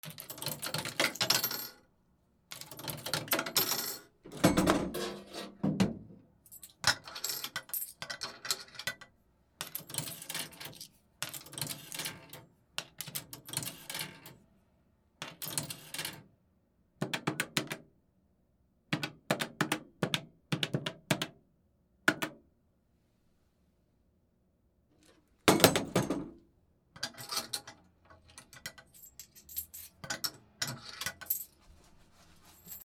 公衆電話